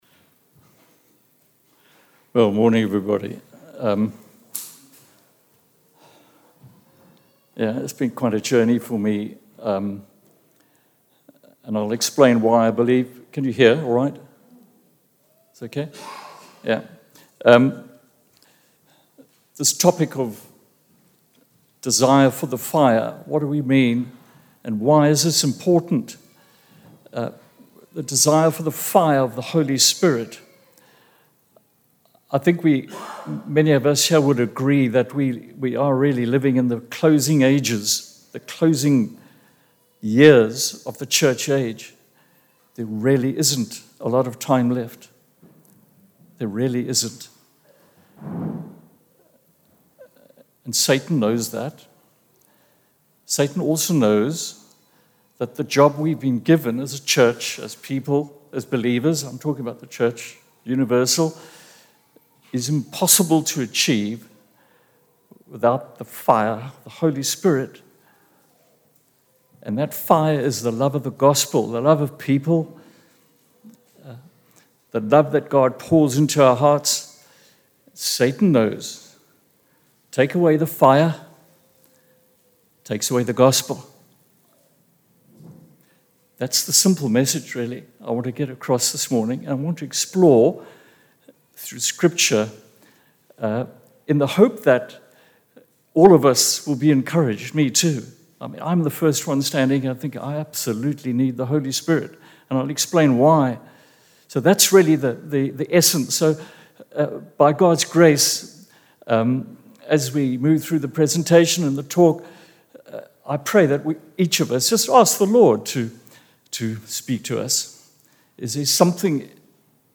PS 1 ::::: The audio file has 2 sections. The first is the sermon. The second is the time of ministry which followed. This constituted a quiet time, then some quiet worship songs led by the band. This was followed by a brief period of prayer.